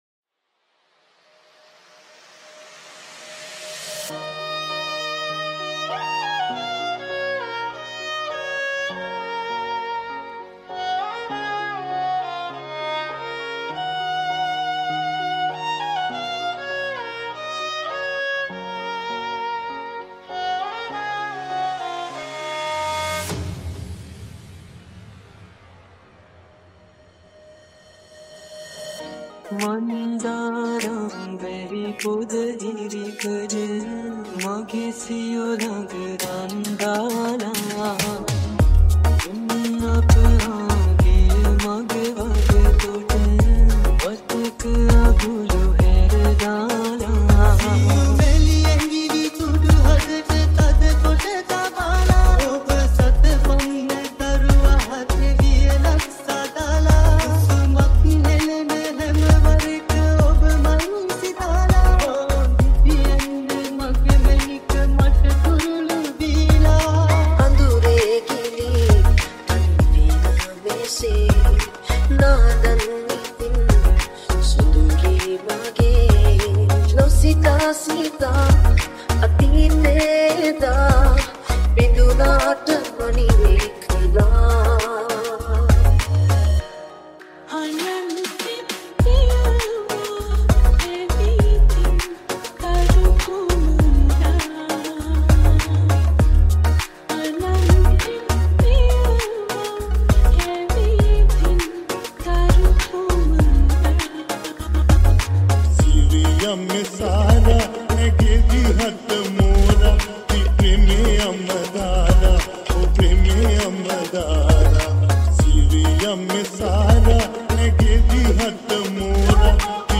New Mashup Song